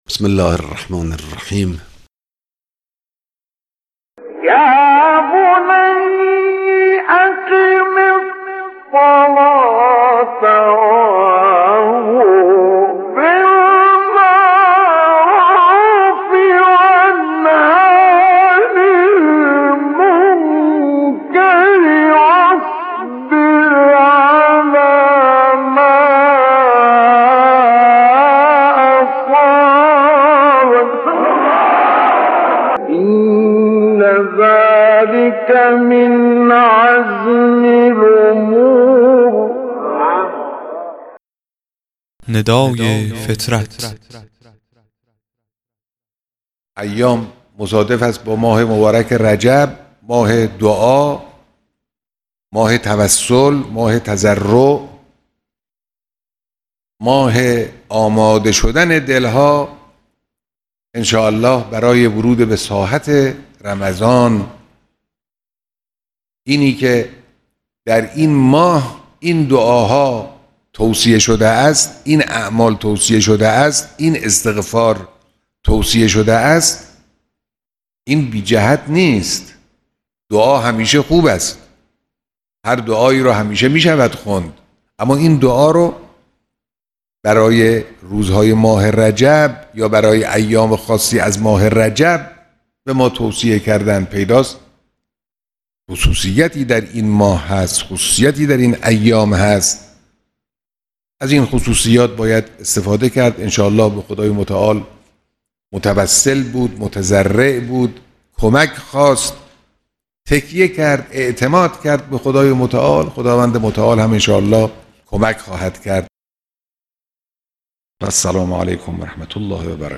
قطعه کوتاه صوتی از امام خامنه ای در خصوص استفاده از ماه رجب